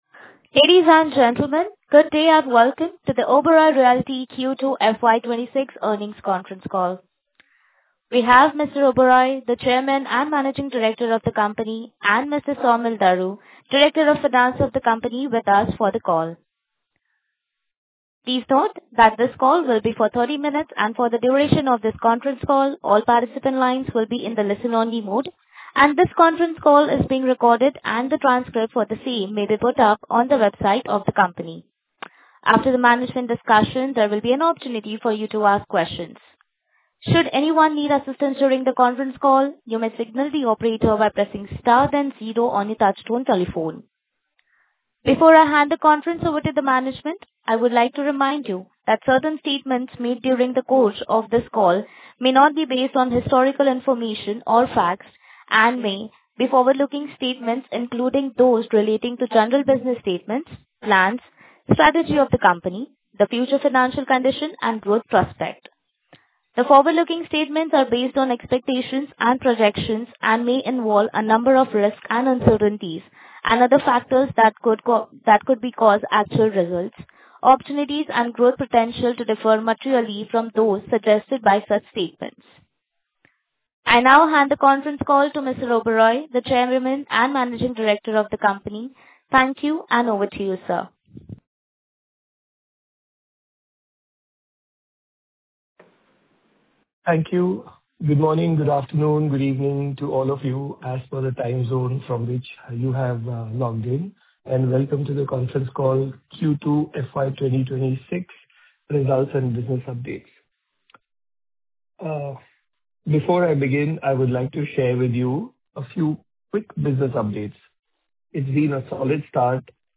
Audio_Recording_FY26_Earnings_Conference_Call_0.mp3